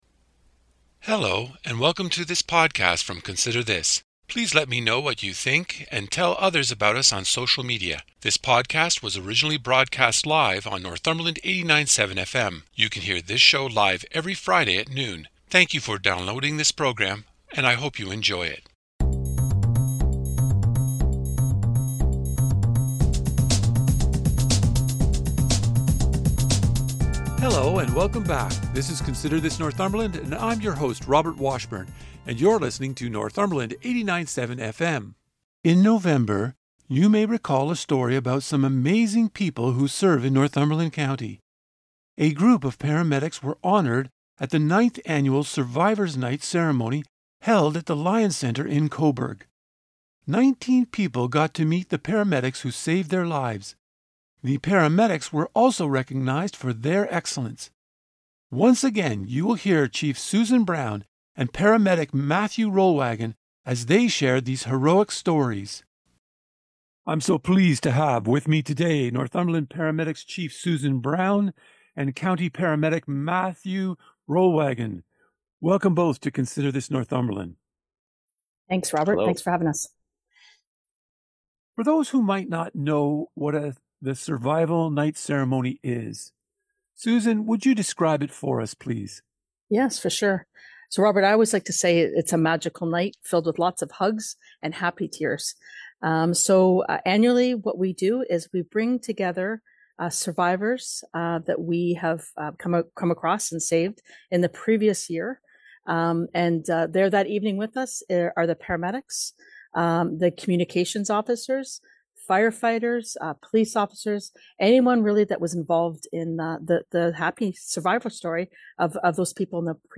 This interview is pulled from the Consider This Northumberland archive of more than 100 interviews done in 2024.